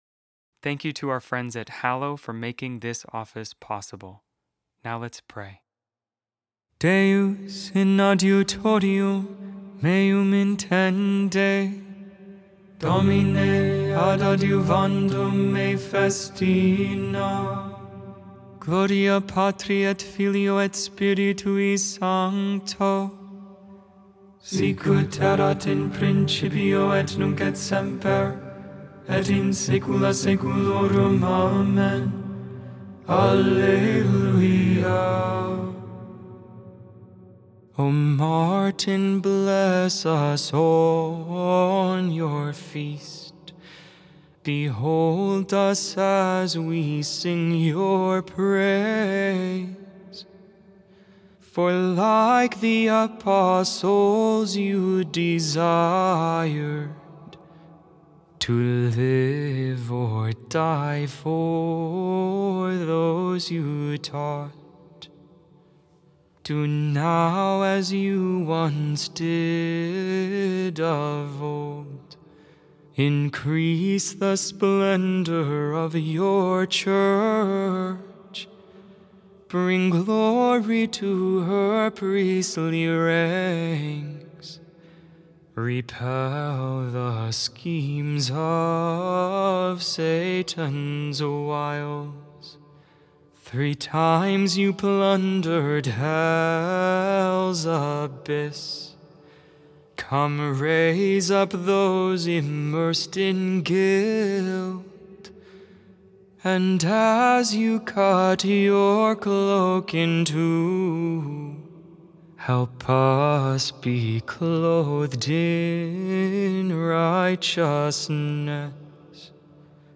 Lauds, Morning Prayer for 32nd Tuesday in Ordinary Time, April 11, 2025.Memorial of St. Martin of Tours, Bishop Made without AI. 100% human vocals, 100% real prayer.